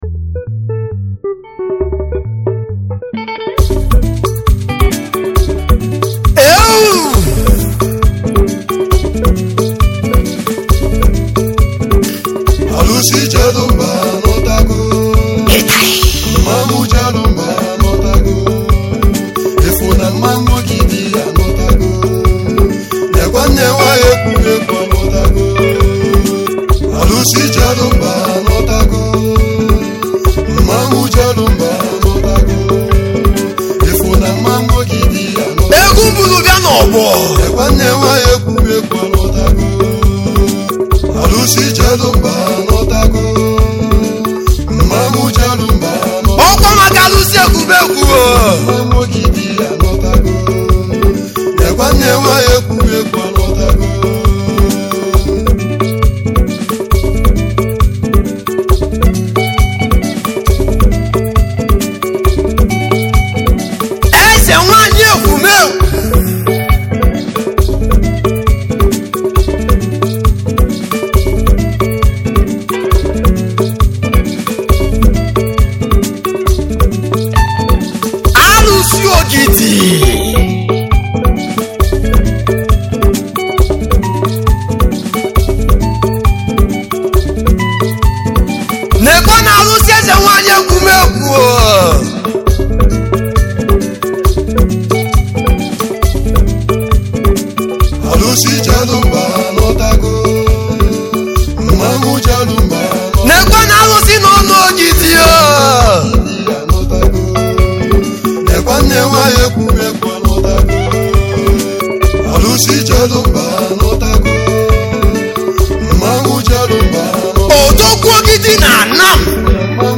igbo highlife
highlife music band